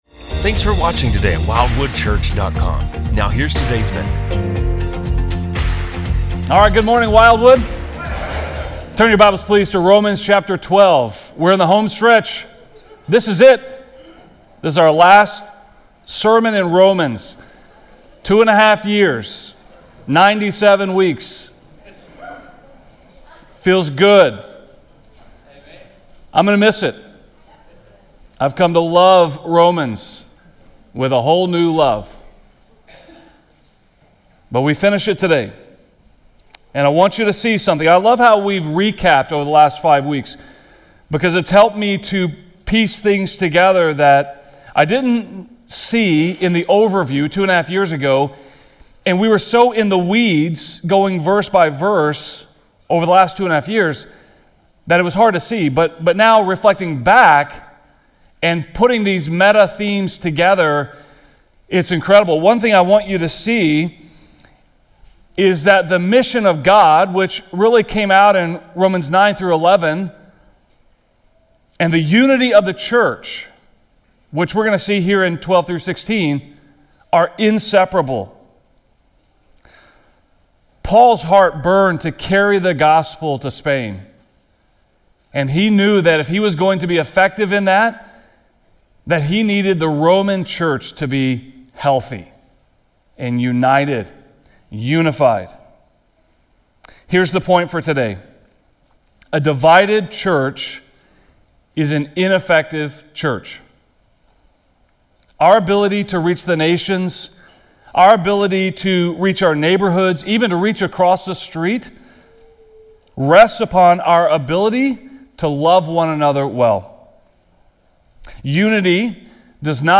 In this sermon, we concluded our series in Romans by exploring chapters 12–16, where Paul moves from explaining the Gospel to showing how it transforms our lives. True worship is offering ourselves as living sacrifices to God, which shapes how we treat others within the church. Paul calls believers to humility, love, service, and unity — emphasizing that a divided church hinders the mission of God.